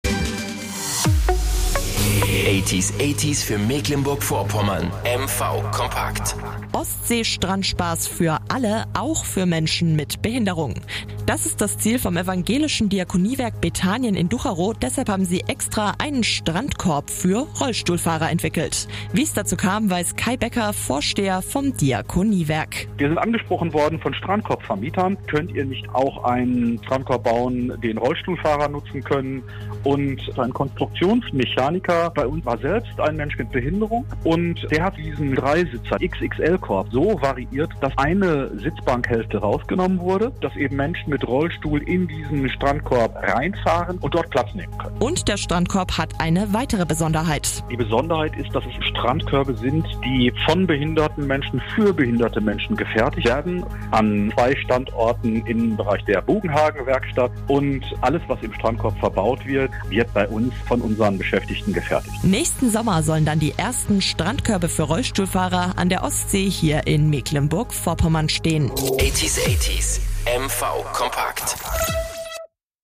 Ein MP3-Audio-Mitschnitt vom Radiosender: